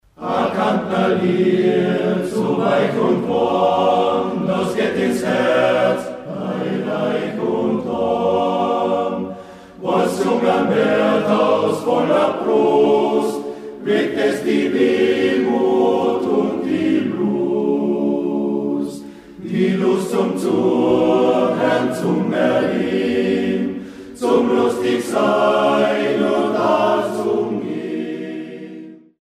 • Aufgenommen im März 2005 in der Volksschule Poggersdorf